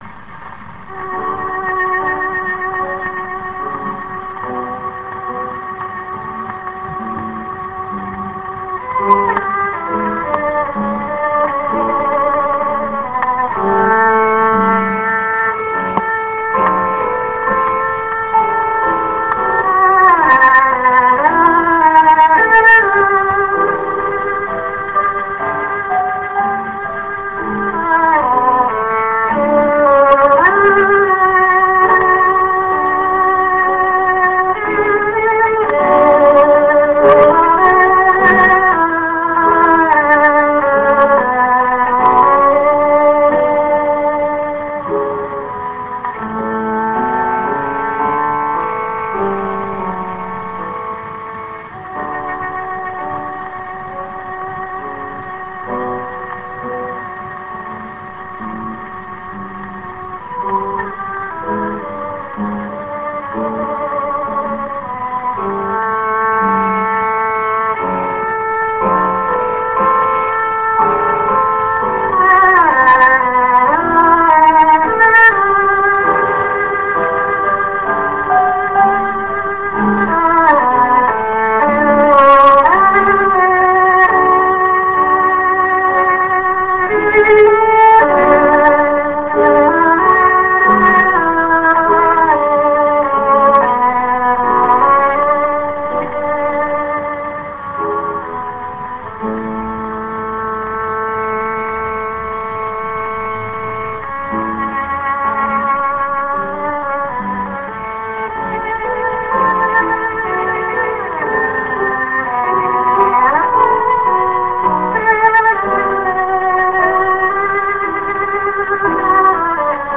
蓄音機から流れる音を録音してみました!!
ノスタルジックな雑音混じりの音楽を
バイオリン*ソロ
12インチSP盤